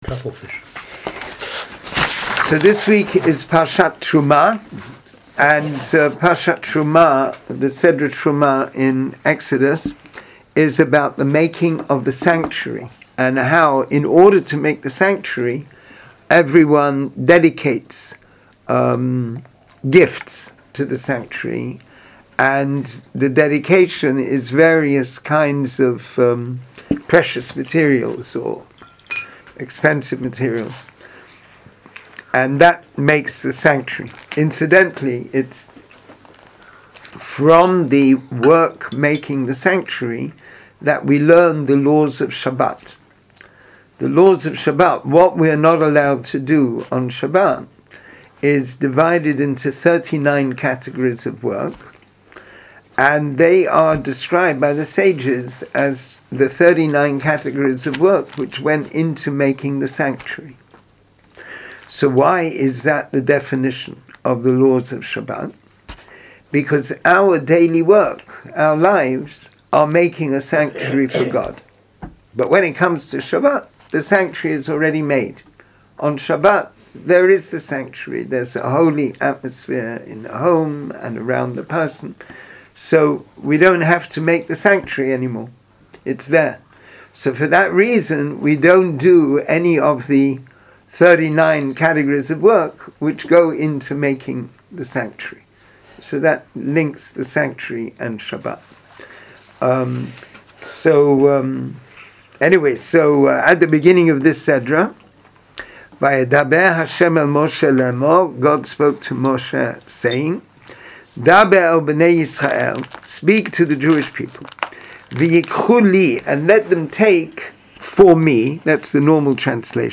Class audio Listen to the class Class material Download the related text Join the class?